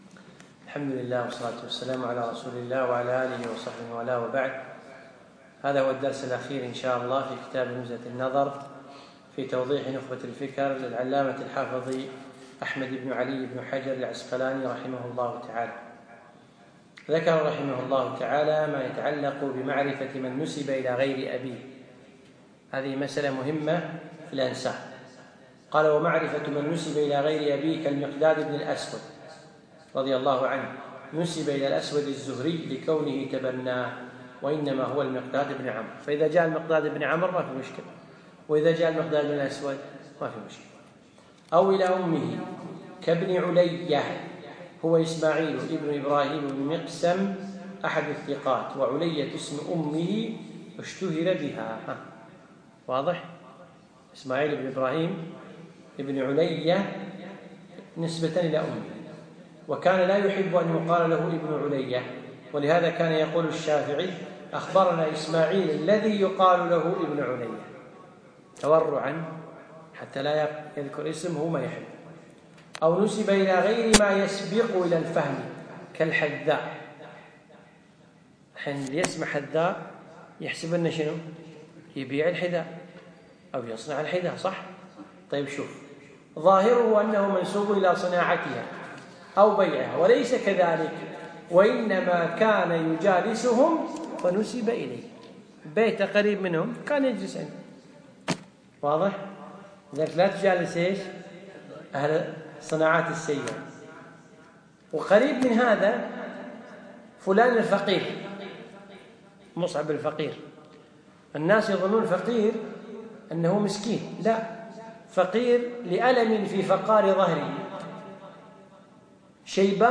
الدرس العشرون والأخير